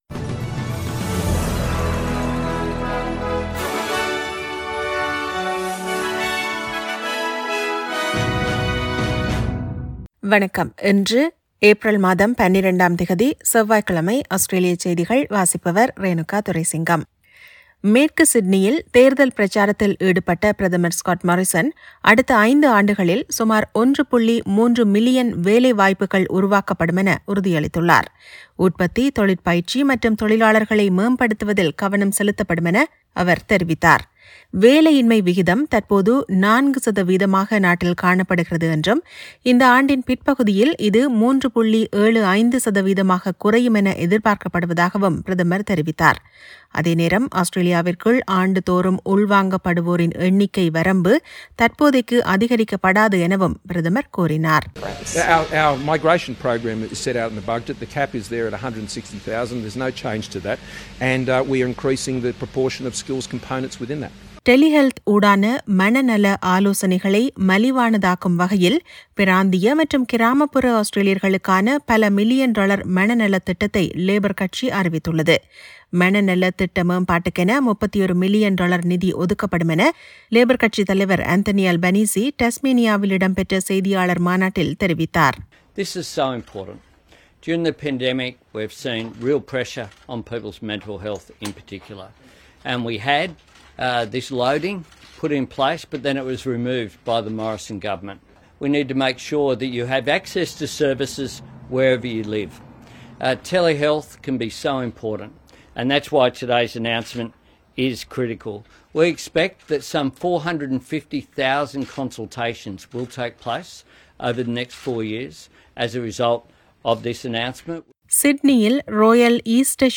Australian news bulletin for Tuesday 12 April 2022.